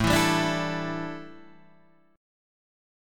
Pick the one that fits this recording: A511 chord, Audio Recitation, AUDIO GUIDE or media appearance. A511 chord